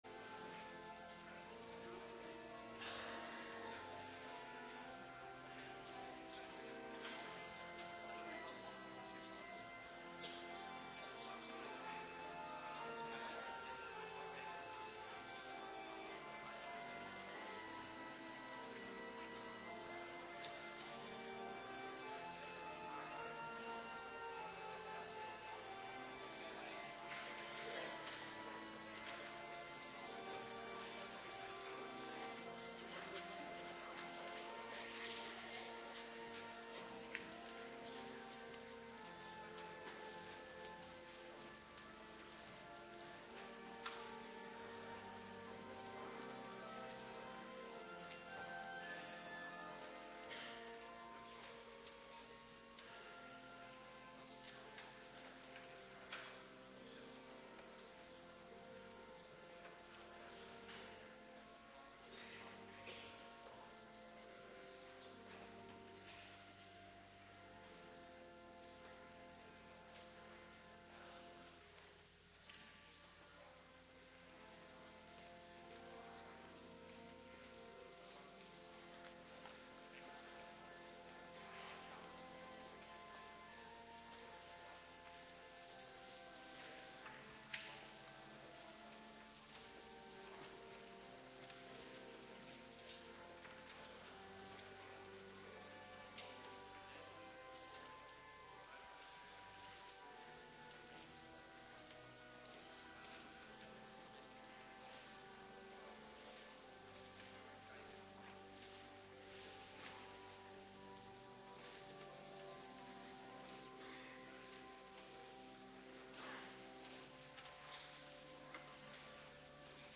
Bij meeluisteren van uitzending is het geluid zwak bij audio luisteren is het geluid beter. is mis gegaan bij installeren wordt komende week verholpen uw begrip hiervoor Ps 100 . 3 en 4 Ps 25 . 4 Kol 3 . 1/17 nav HC Zondag 33 Ps 51 . 6 en 7 Ps 17 . 3 en 8 Ps 68 . 2 Thema: Ware bekering 1/. Hartelijk berouw 2/. Hartelijke blijdschap